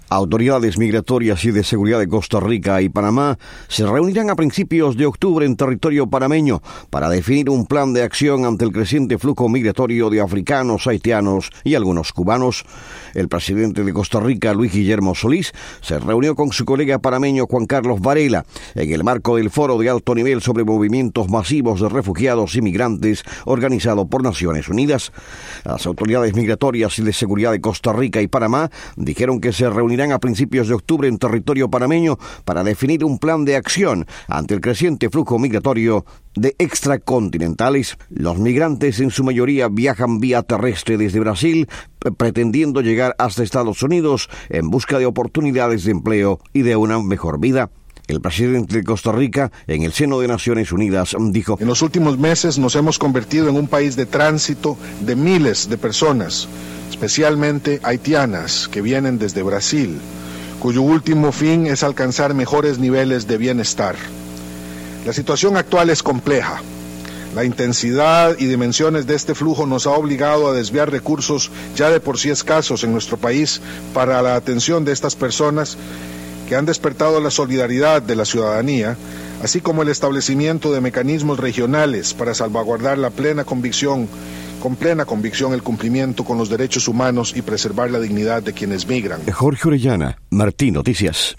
Declaración de Solís en cumbre de ONU sobre migrantes